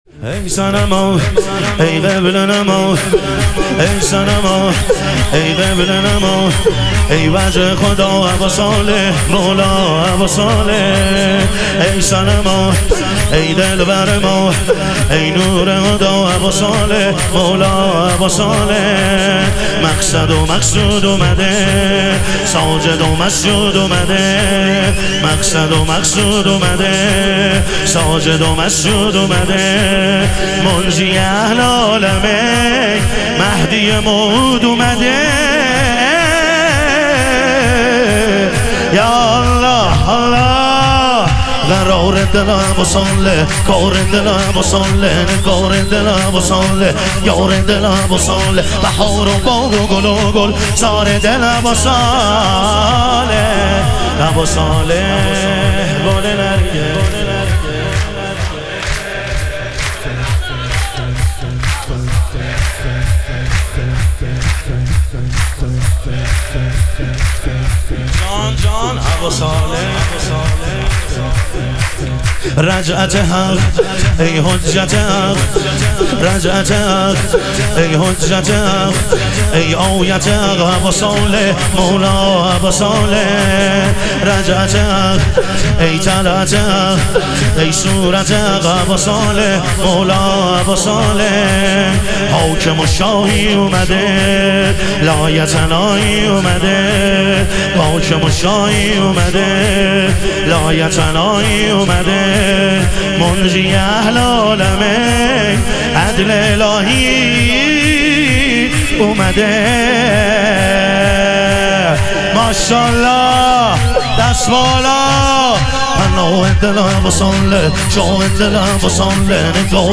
شور
شب ظهور وجود مقدس حضرت مهدی علیه السلام